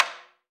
AFRO.TAMB2-S.WAV